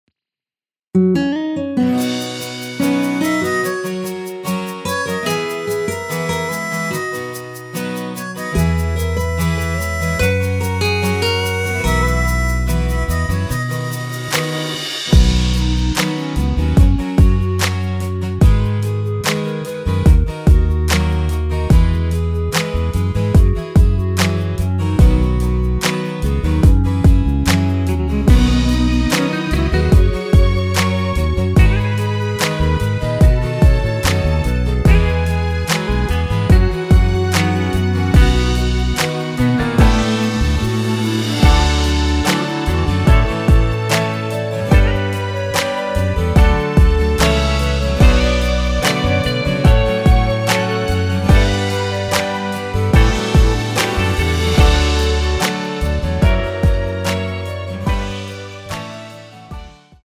원키에서(-10)내린 멜로디 포힘된 MR입니다.(미리듣기 확인)
멜로디 MR이라고 합니다.
앞부분30초, 뒷부분30초씩 편집해서 올려 드리고 있습니다.
중간에 음이 끈어지고 다시 나오는 이유는